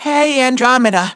synthetic-wakewords
ovos-tts-plugin-deepponies_Teddie_en.wav